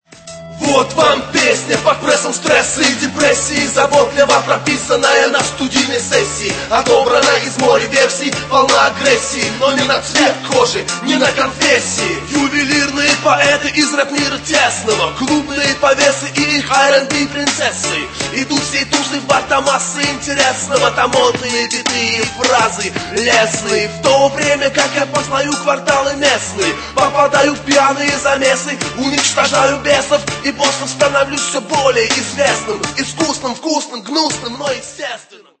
рэп музыка